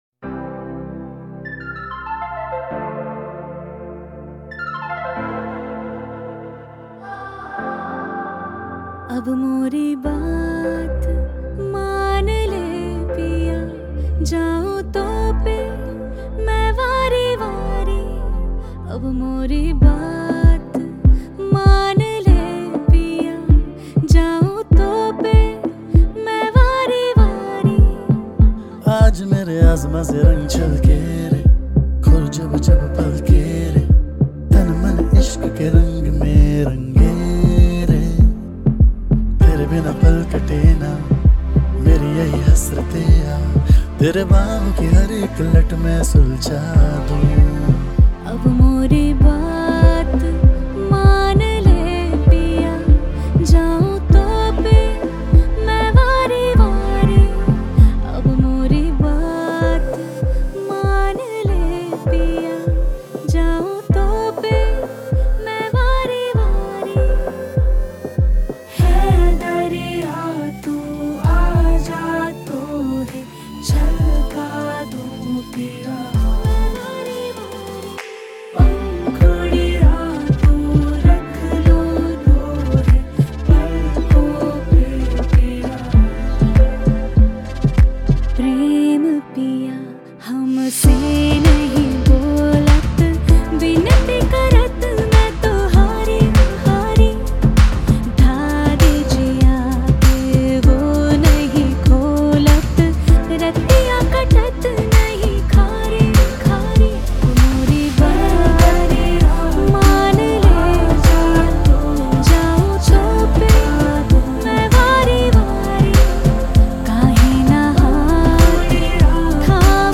INDIPOP MP3 Songs
IndiPop Music Album